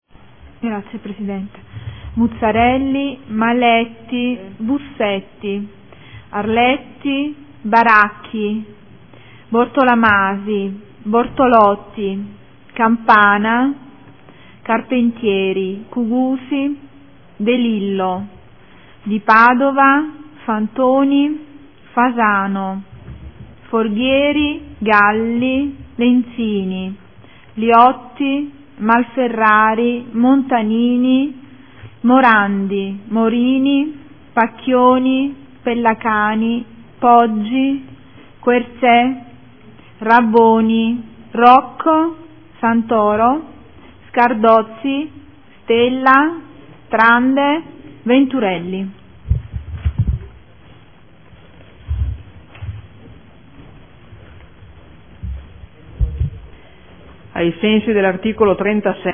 Seduta del 09/04/2015 Appello.
Segretaria